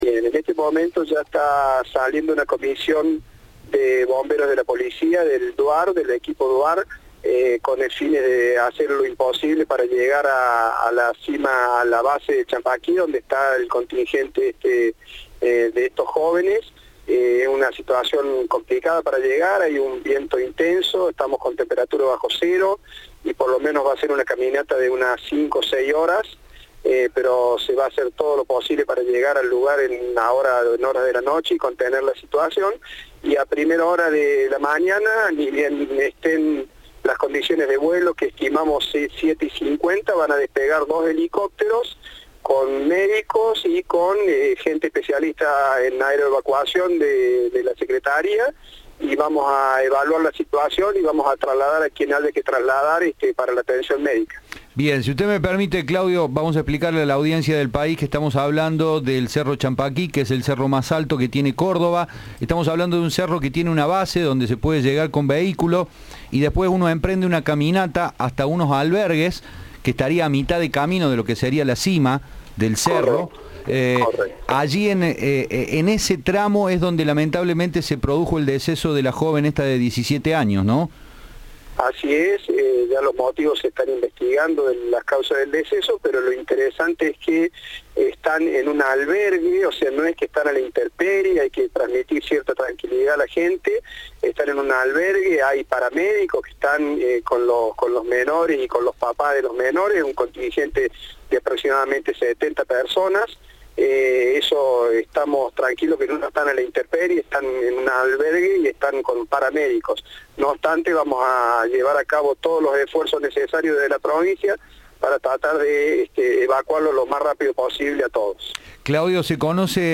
En diálogo con Cadena 3, el secretario de Gestión de Riesgo Climático y Catástrofes de la Provincia, Claudio Vignetta, reveló que diagramaron un operativo especial para asistir a los alumnos y sus acompañantes.